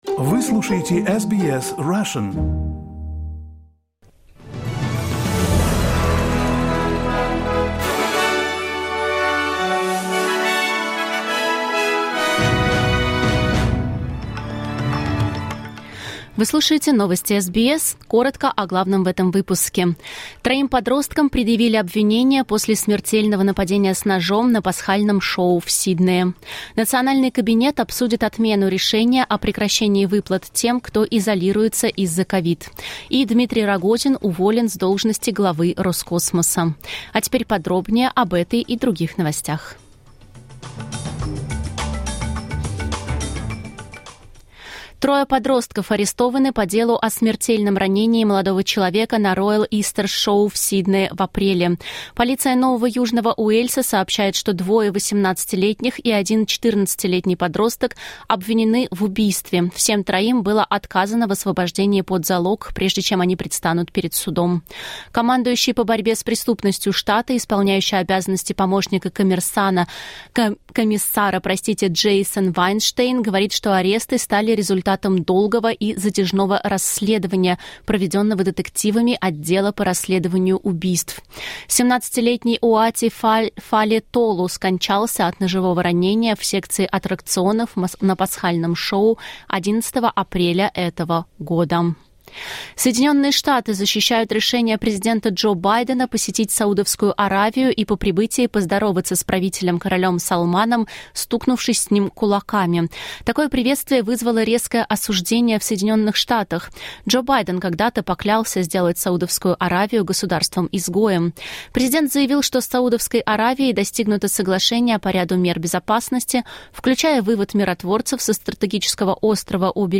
SBS News in Russian - 16.07.2022